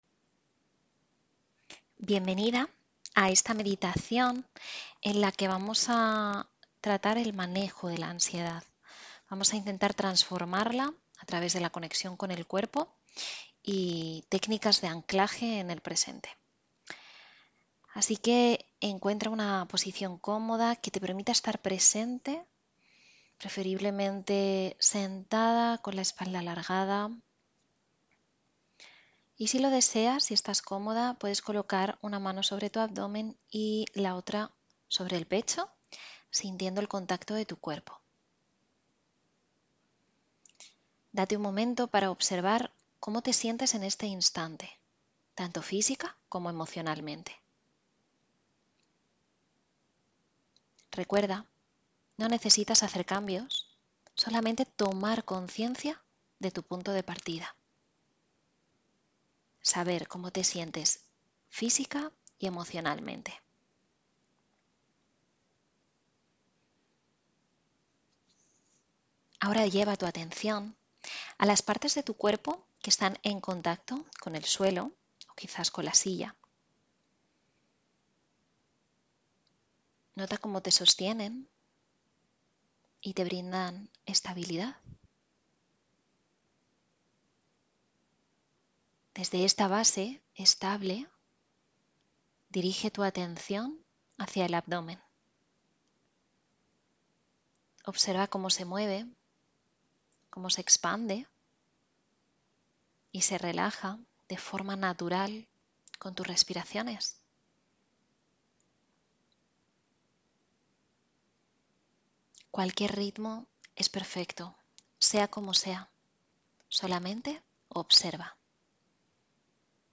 Sesión 9: Meditación para el Manejo de la Ansiedad